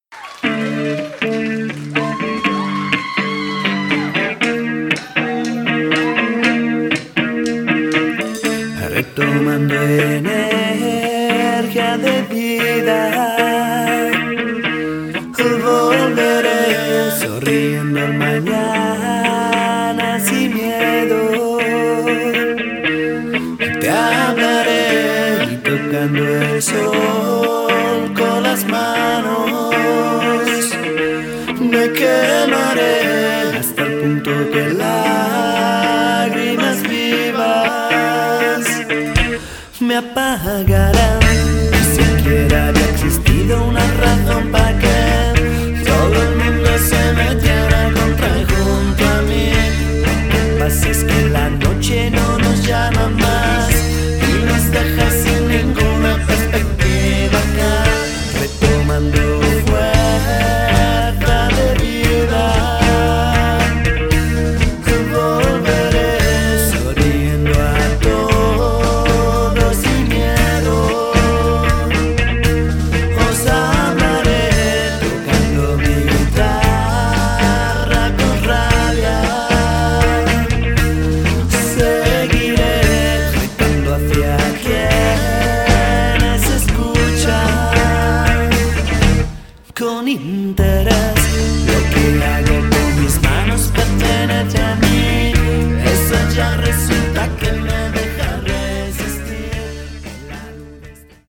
rock music
Genere: Rock.